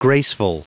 Prononciation du mot graceful en anglais (fichier audio)
Prononciation du mot : graceful